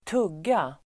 Uttal: [²t'ug:a]